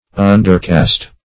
undercast - definition of undercast - synonyms, pronunciation, spelling from Free Dictionary
Undercast \Un`der*cast"\